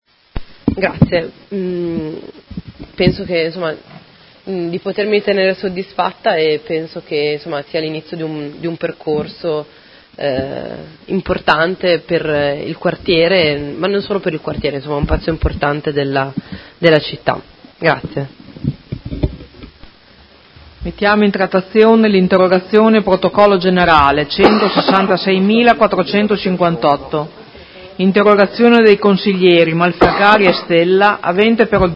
Sedute del 10/01/2019 Replica a risposta Assessore Guerzoni. Interrogazione della Consigliera Di Padova (PD) avente per oggetto: Quale futuro per la Chiesa di San Lazzaro?